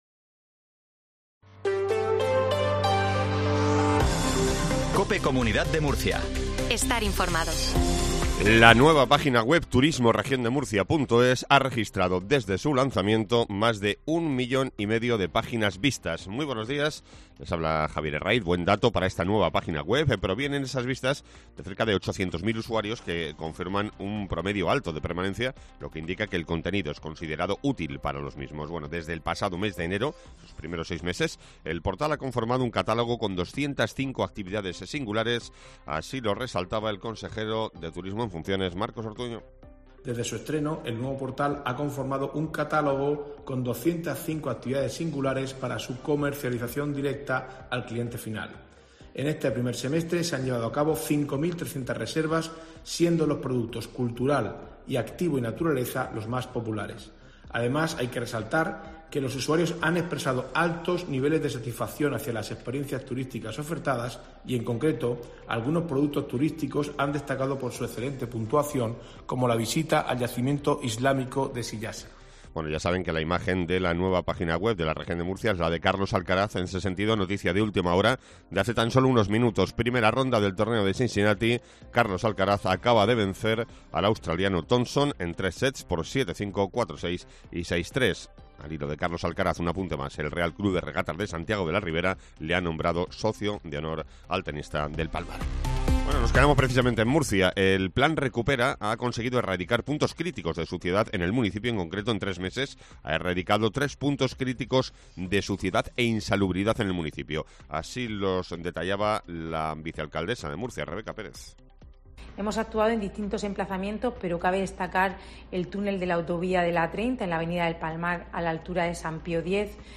INFORMATIVO MATINAL REGION DE MURCIA 0720